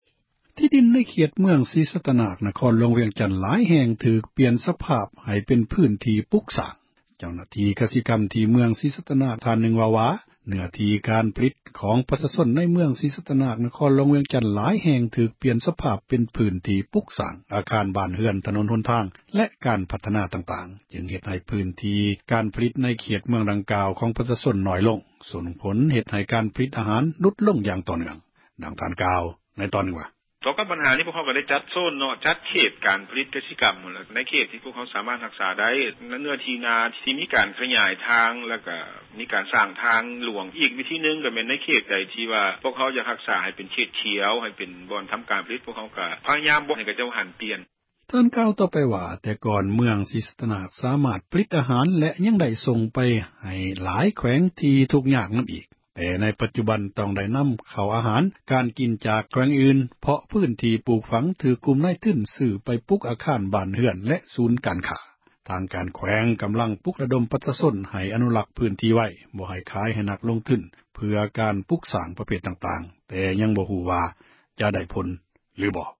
ເຈົ້າຫນ້າທີ່ ກະສິກັມ ທີ່ ເມືອງ ສີສັຕນາກ ທ່ານນຶ່ງ ເວົ້າວ່າ ເນຶ້ອທີ່ ການຜລິດ ຂອງ ປະຊາຊົນ ຫລາຍແຫ່ງ ຖືກປ່ຽນ ສະພາບ ເປັນພຶ້ນທີ່ ປຸກສ້າງ ອາຄານ ບ້ານເຮືອນ ຖນົນຫົນທາງ ແລະ ການພັທນາ ຕ່າງໆ ເຮັດໃຫ້ ພຶ້ນທີ່ ການຜລິດ ໃນເຂດ ເມືອງ ຫນ້ອຍລົງ ສົ່ງຜົລໃຫ້ ການຜລິດ ອາຫານ ຫລຸດລົງ ຢ່າງຕໍ່ເນຶ່ອງ.